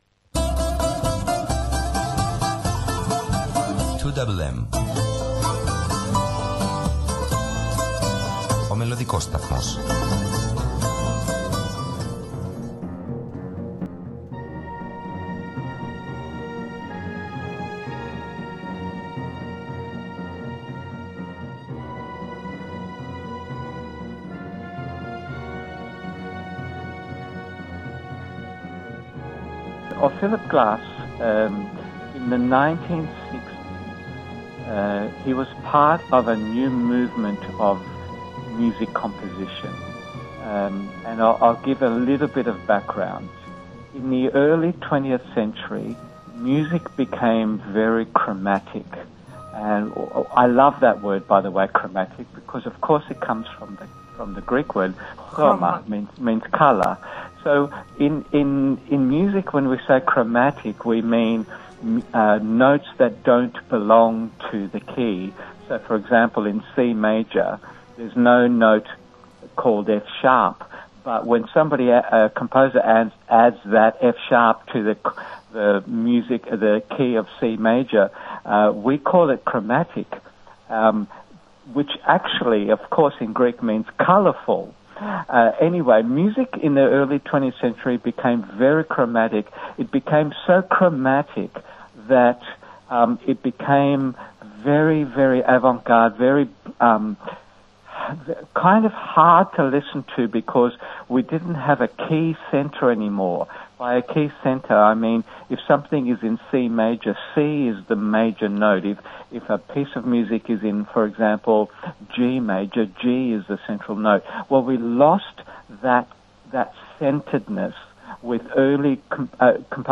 σε μία εκ βαθέων συνέντευξη